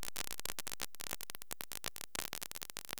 pin4-standalone-unusable.wav